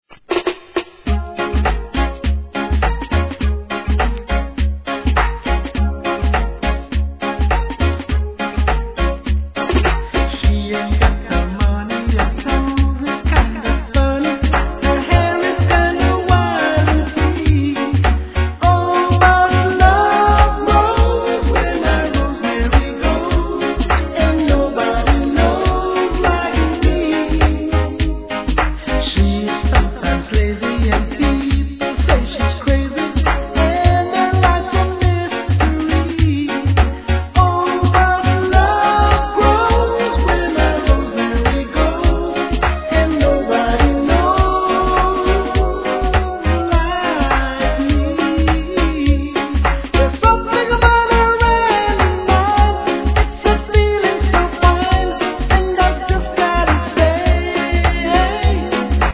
REGGAE
やわらかく軽快なRHYTHMで優しく歌い上げる名曲カヴァー♪